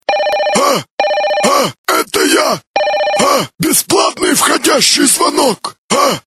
Категория: Рингтоны пародии